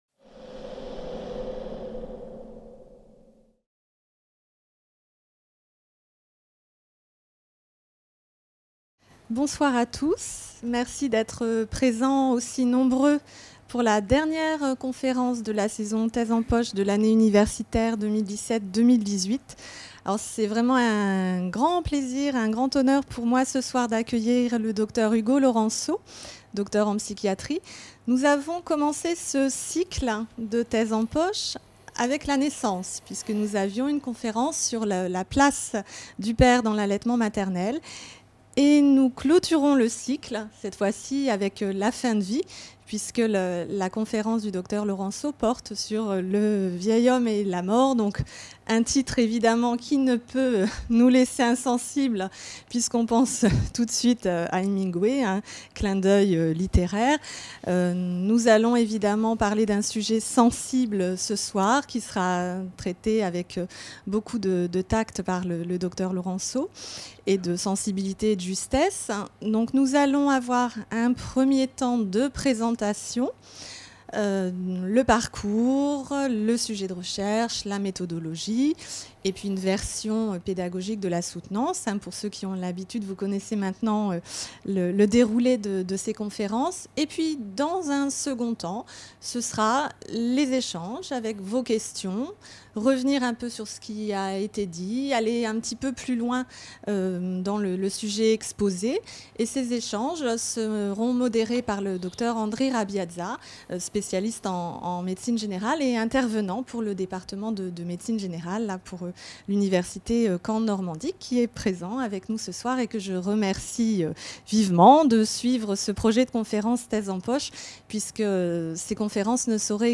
Les conférences santé de la BU